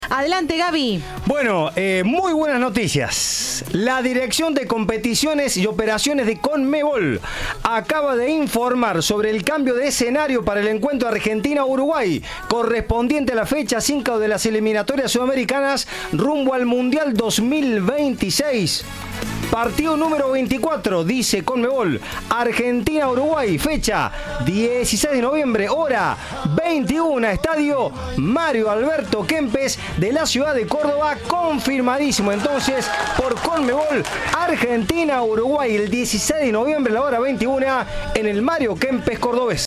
Al respecto, el titular de la Agencia Córdoba Deportes, Héctor "Pichi" Campana, habló con Cadena 3 y se mostró optimista de que "La Scaloneta" juegue en el Kempes.